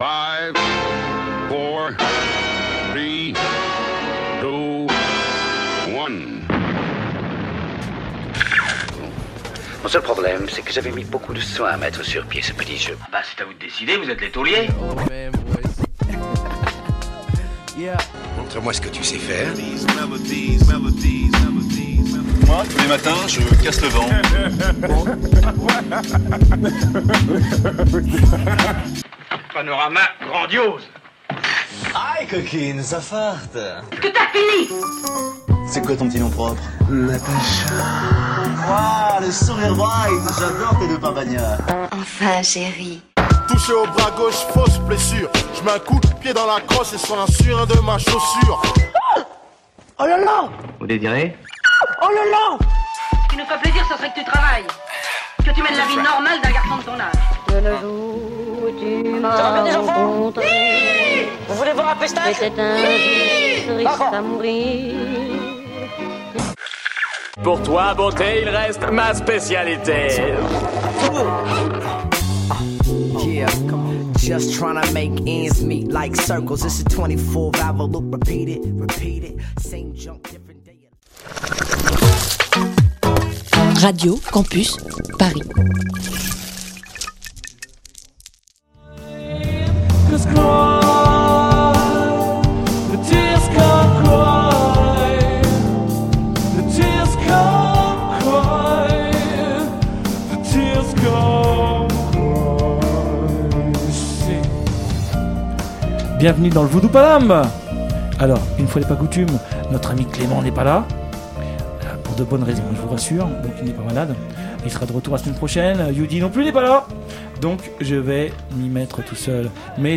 Émission du mercredi 05 Mai 2017